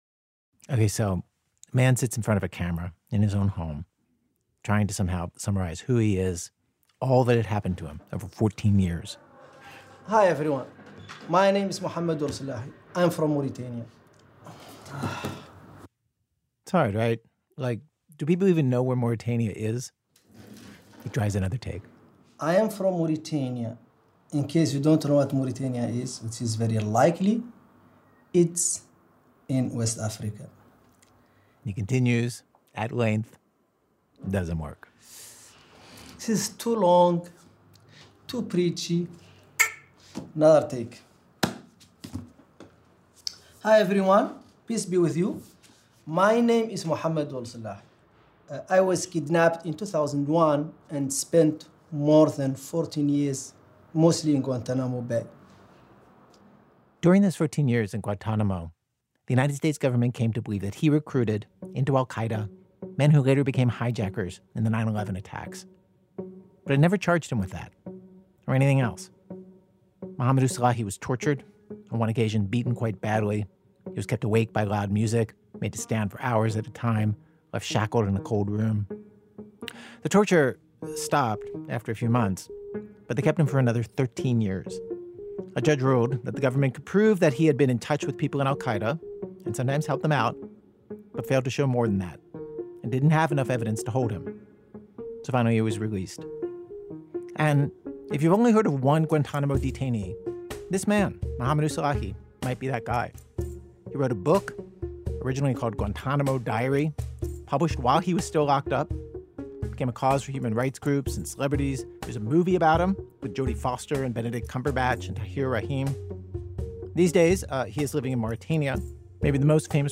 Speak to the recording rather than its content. Note: The internet version of this episode contains un-beeped curse words. The conversations in this show were recorded for a documentary, “In Search of Monsters.”